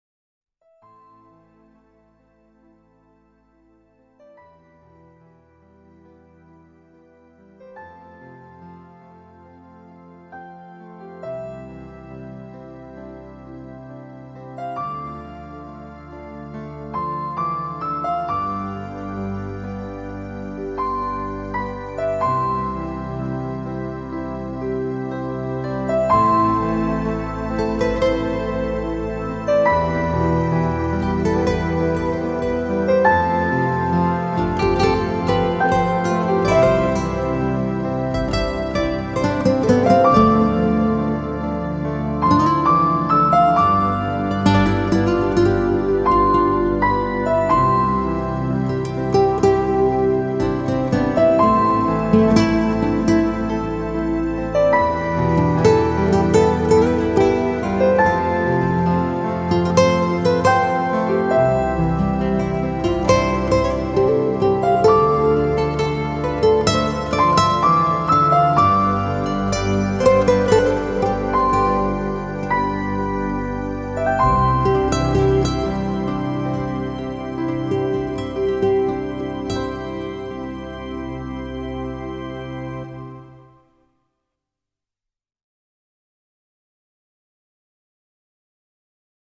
【缠绵的弦乐】
音乐类型：New Age
专辑中包含14首音乐，优美、神秘、抒情，强弱音的转折表
吉他、钢琴、长笛、竖琴，演奏出了一部壮丽、神秘的“生命之
流畅的吉他、深情的钢琴、感性的长笛、缠绵的